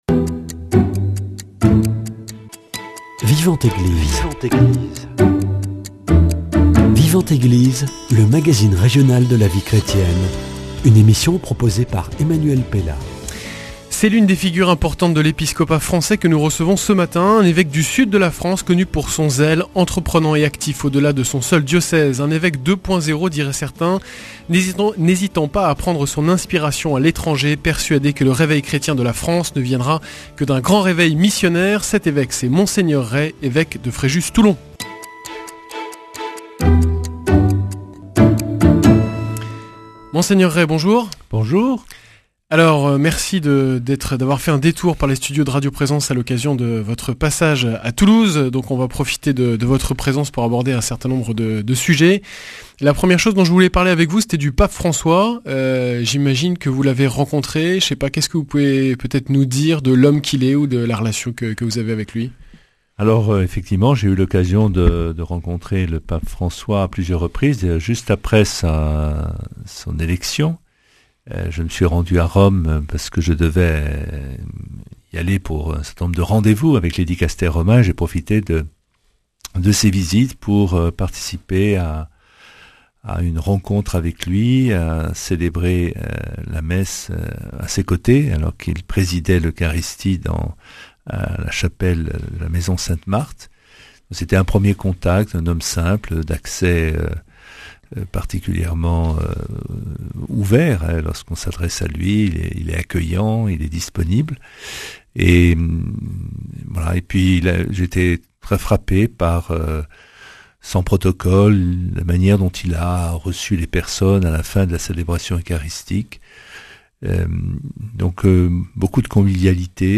Avec Mgr Dominique Rey, évêque de Fréjus-Toulon.
C’est l’une des figures importantes de l’épiscopat français que nous recevons ce matin.